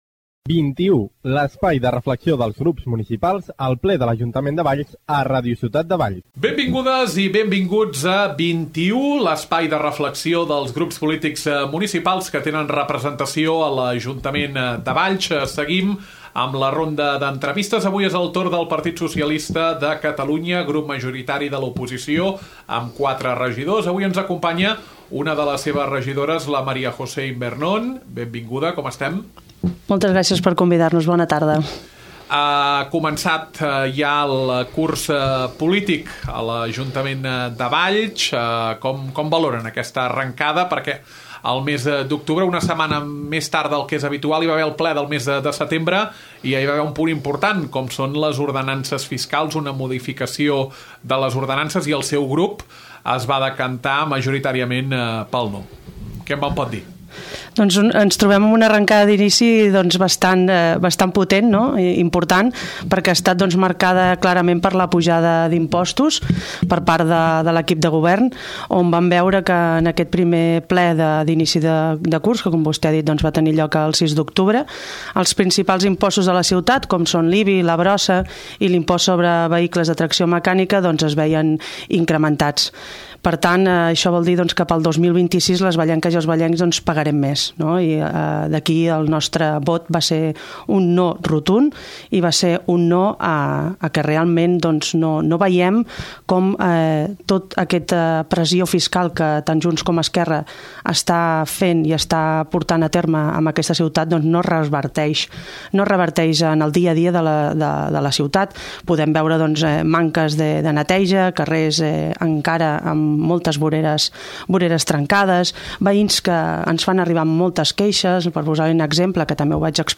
Avui és el torn del PSC, grup majoritari de l’oposició. Conversa amb la regidora, Maria José Invernón.